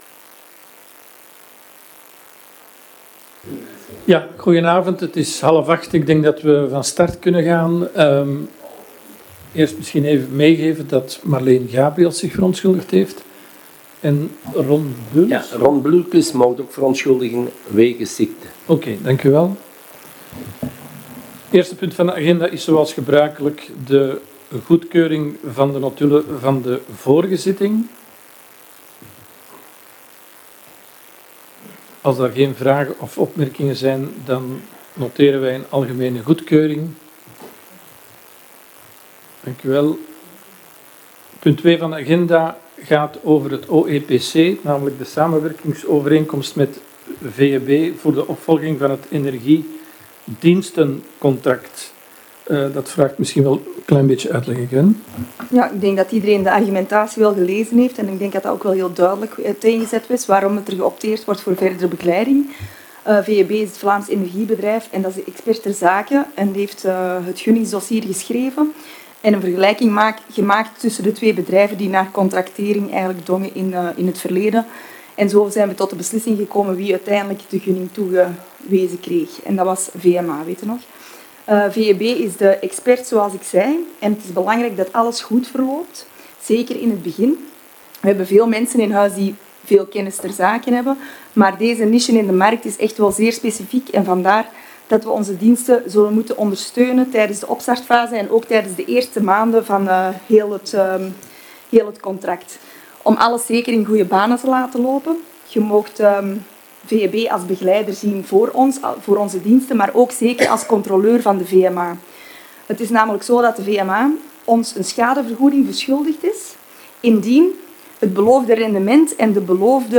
Gemeentehuis